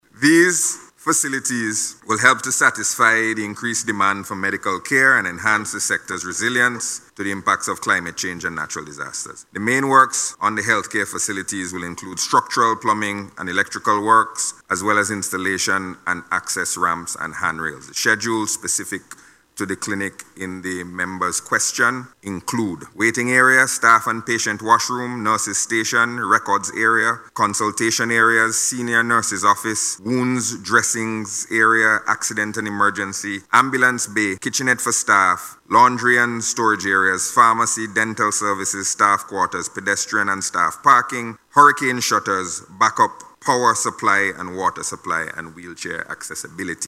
He was responding to a question in the House of Assembly yesterday.